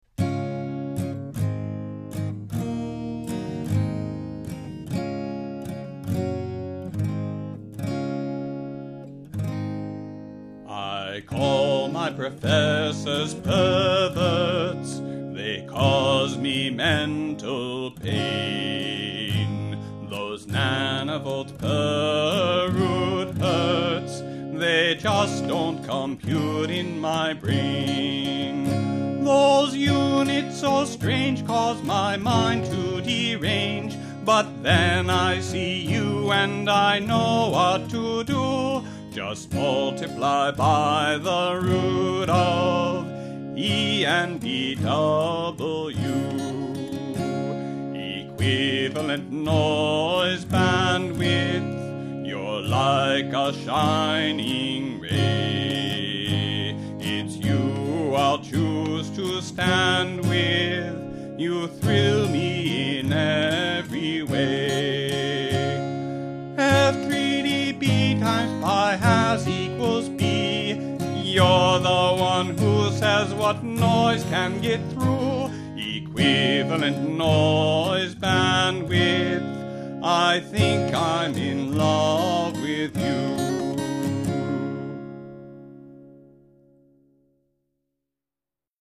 RealAudio Guitar